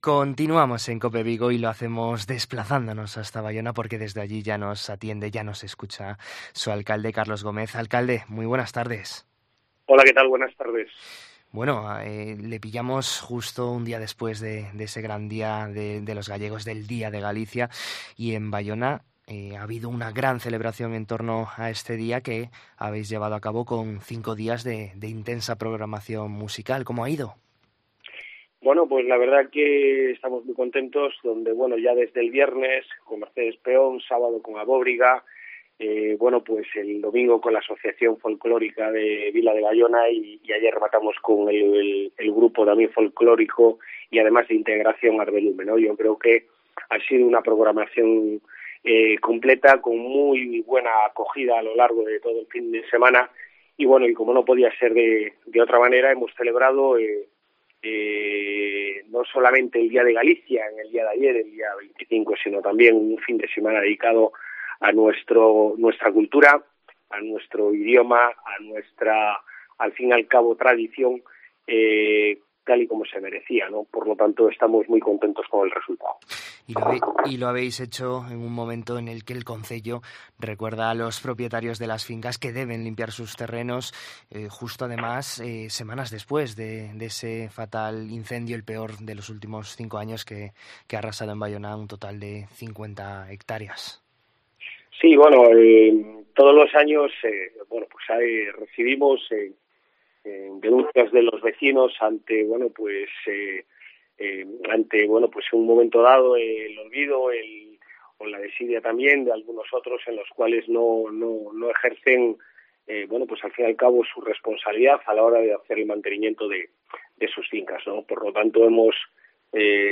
En COPE Vigo conocemos la actualidad de Baiona de la mano de su alcalde, Carlos Gómez